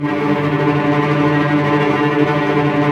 Index of /90_sSampleCDs/Roland LCDP08 Symphony Orchestra/STR_Vcs Bow FX/STR_Vcs Trem wh%